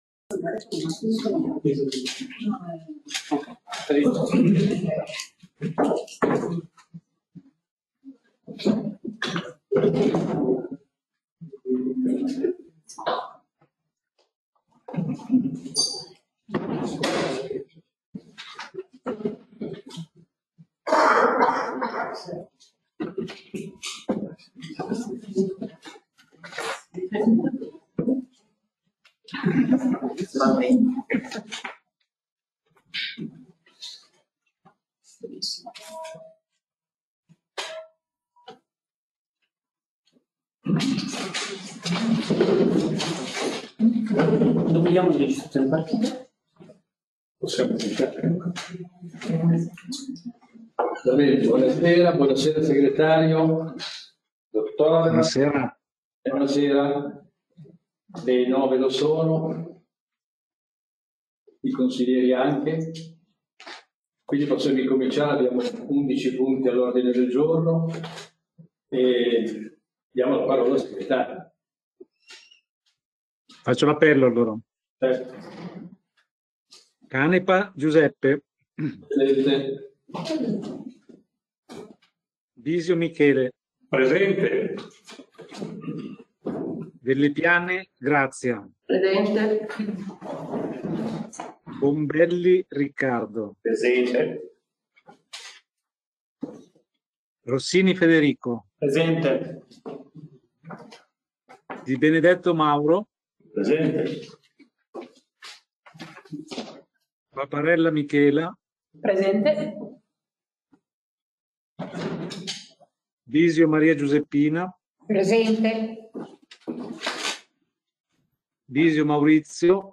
Seduta del Consiglio Comunale del 21/06/2024